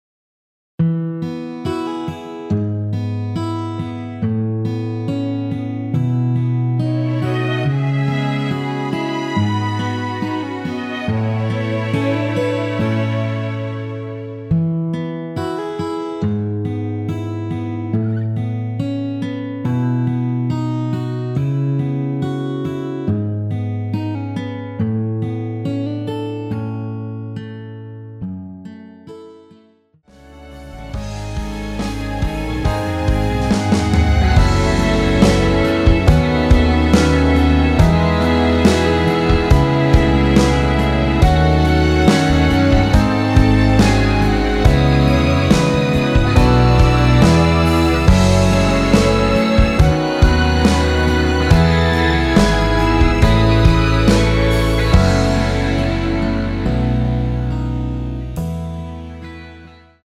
원키에서(+2)올린 MR입니다.(미리듣기 참조)
앞부분30초, 뒷부분30초씩 편집해서 올려 드리고 있습니다.